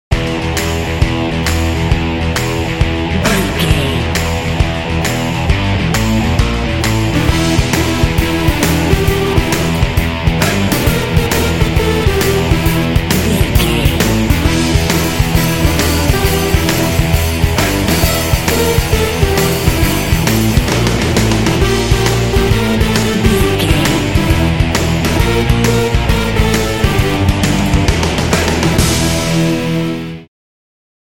Aeolian/Minor
driving
funky
energetic
bouncy
bass guitar
electric guitar
drums
synthesiser